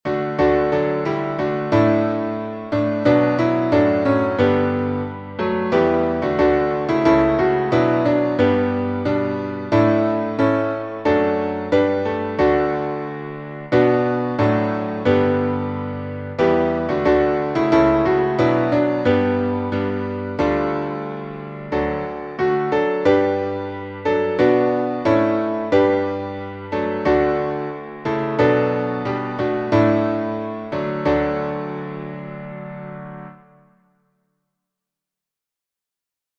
Key signature: D major (2 sharps)